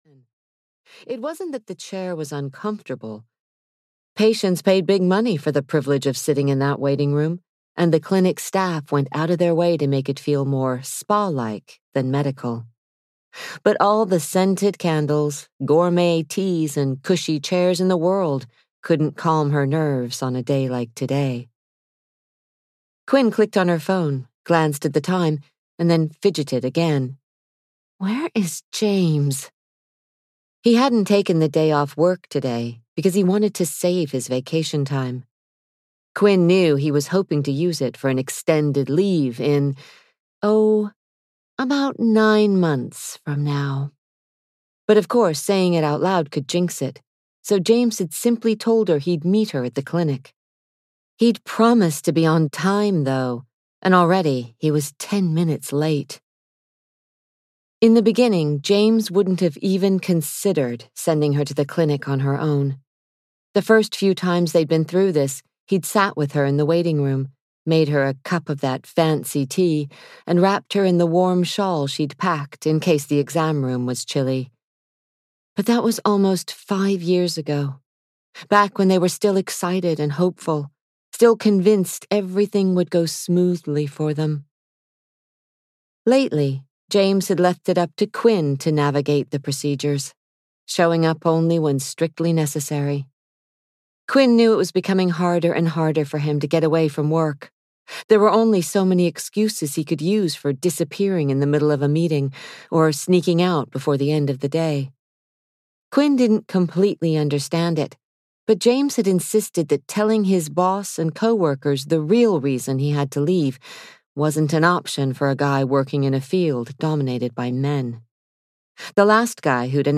Our Stolen Child (EN) audiokniha
Ukázka z knihy